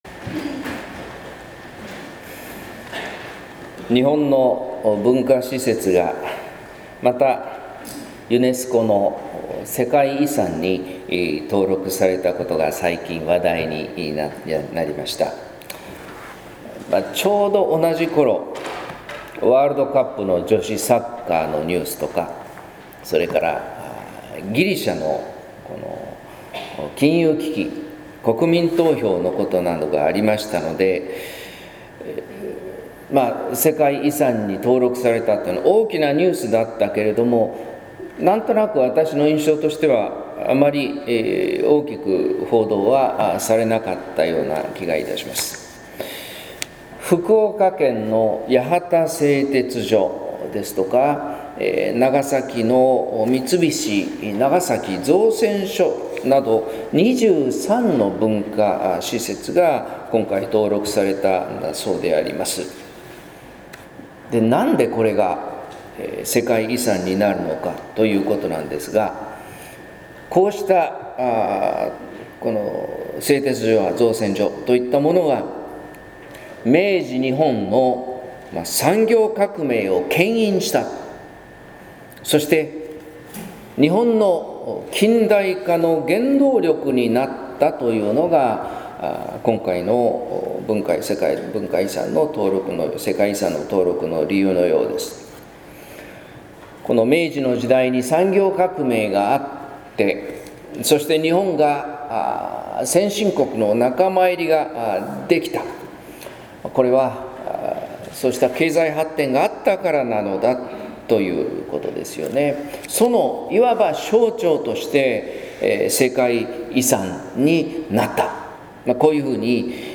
説教「種と土」（音声版） | 日本福音ルーテル市ヶ谷教会